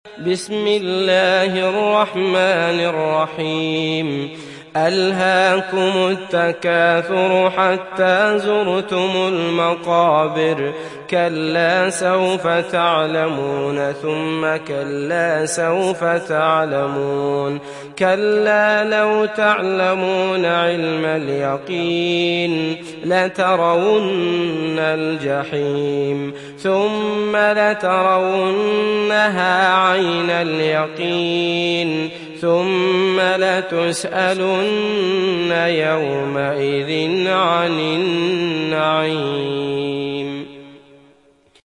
تحميل سورة التكاثر mp3 بصوت عبد الله المطرود برواية حفص عن عاصم, تحميل استماع القرآن الكريم على الجوال mp3 كاملا بروابط مباشرة وسريعة